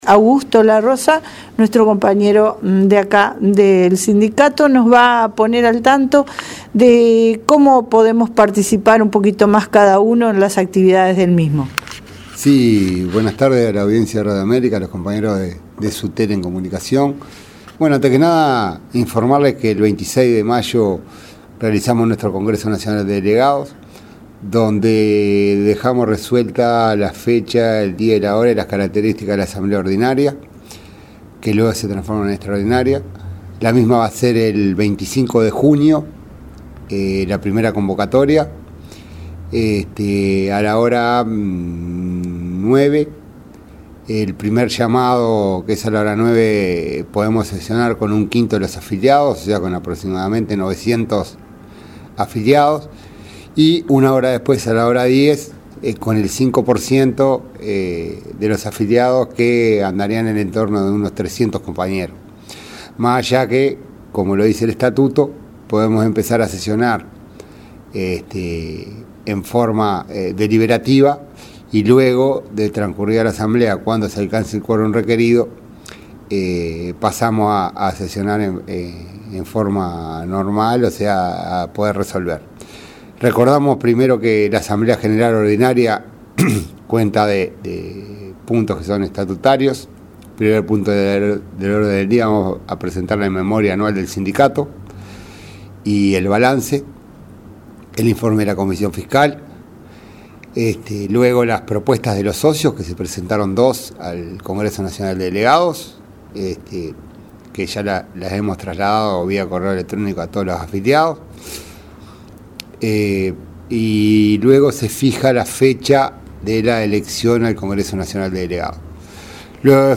Sutel en Comunicación – Audición Radial
Entrevista Sutel lunes 13 de Junio.mp3